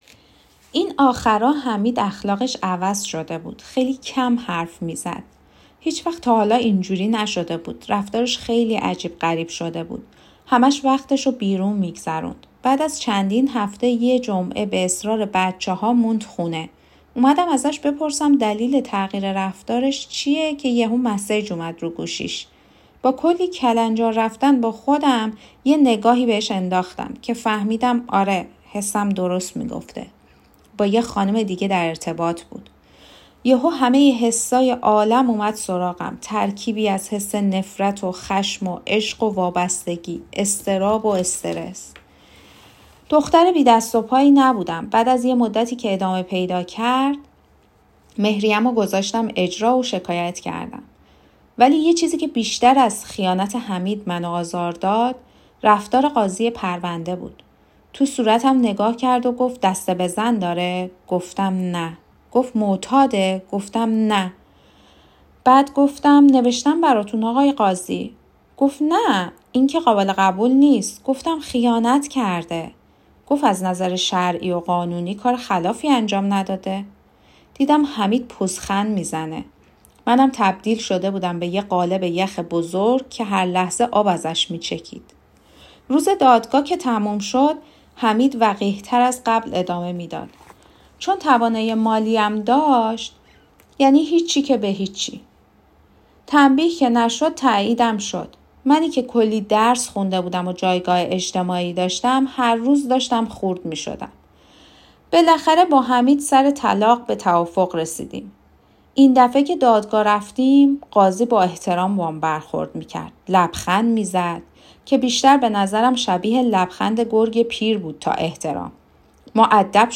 داستان صوتی